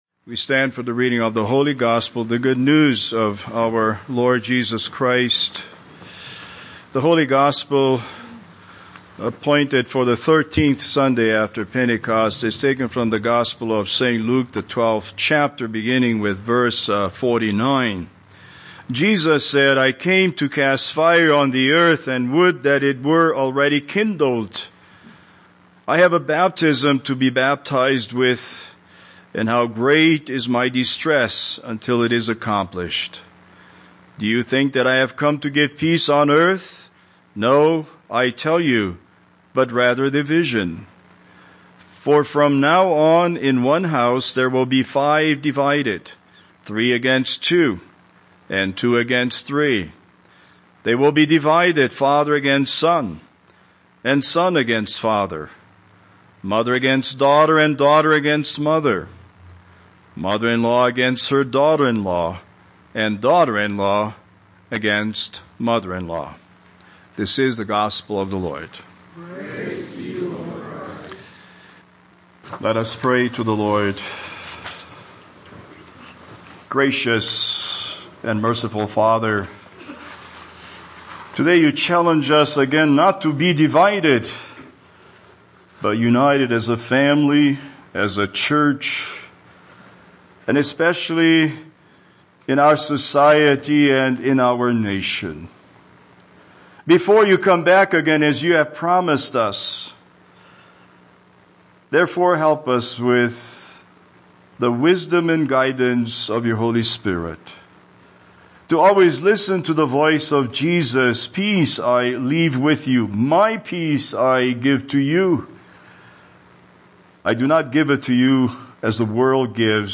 Speaker: Vacancy Pastor